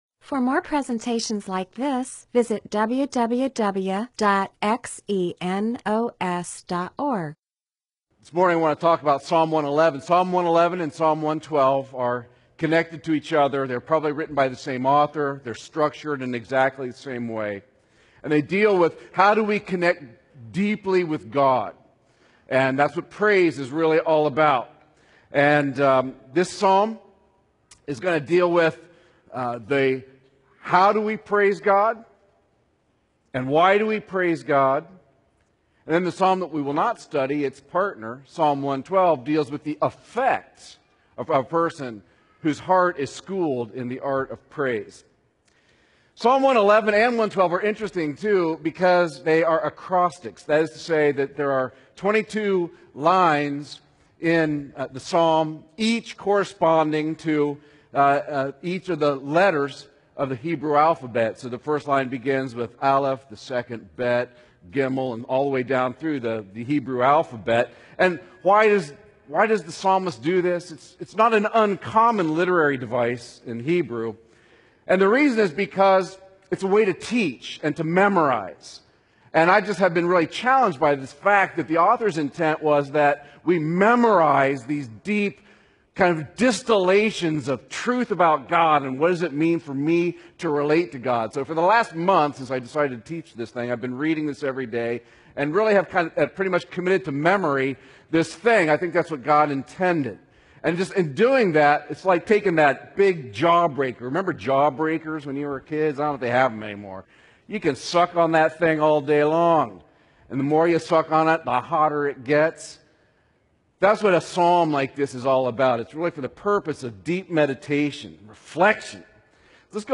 MP4/M4A audio recording of a Bible teaching/sermon/presentation about Psalms 111.